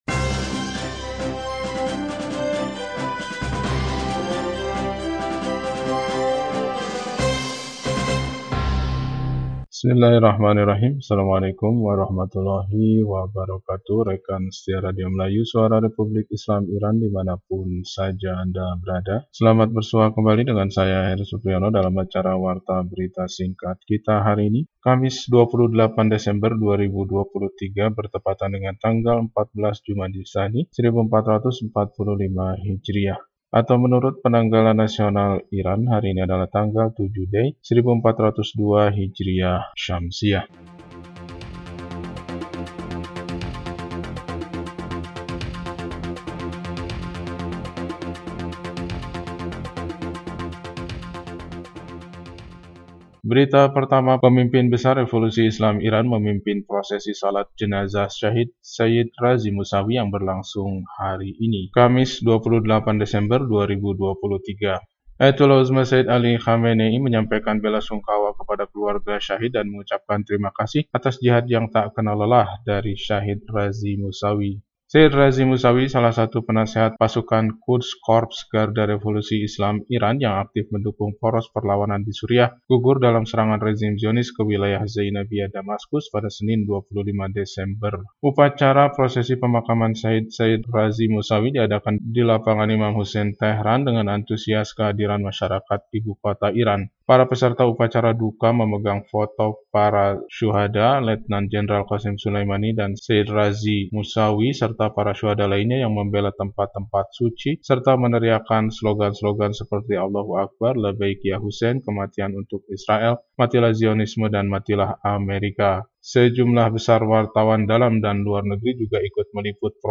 Warta Berita 28 Desember 2023